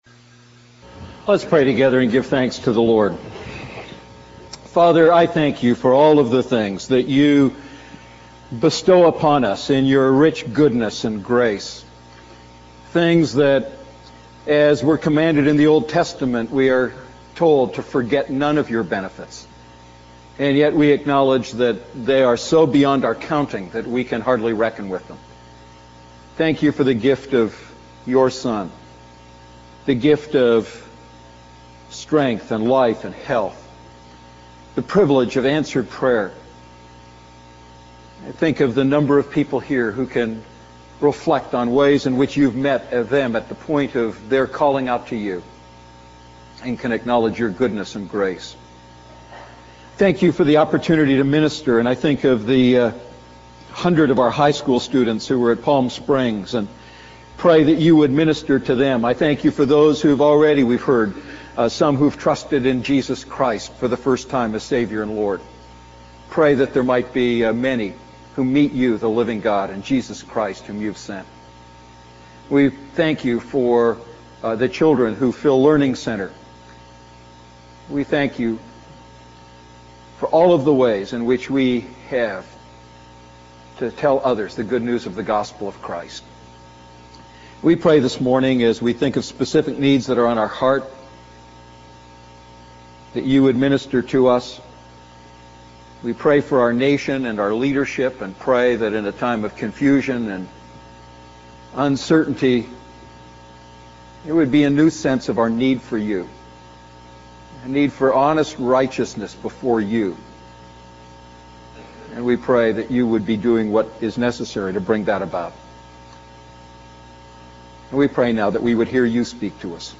A message from the series "I John Series."